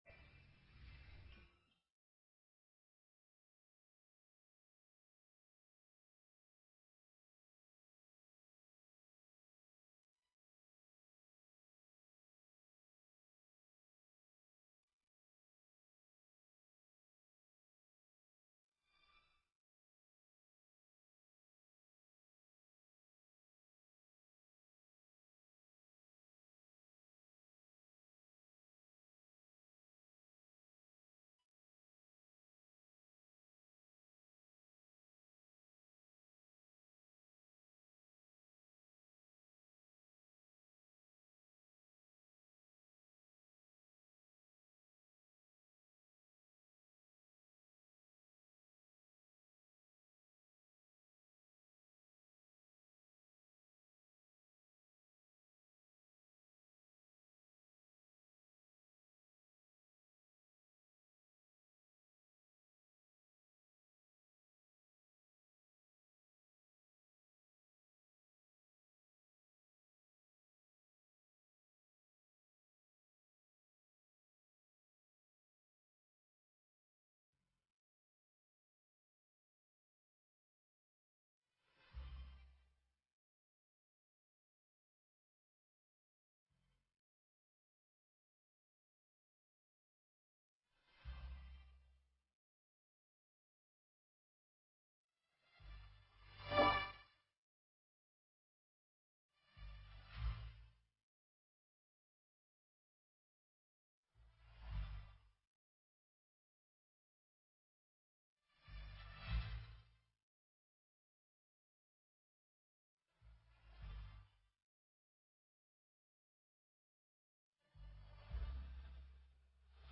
Mp3 Pháp âm Nghệ thuật truyền thông trong pháp thoại – thầy Thích Nhật Từ giảng tại Khóa Hoằng Pháp TƯ, Chùa Bằng, Hà Nội, ngày 18 tháng 10 năm 2008.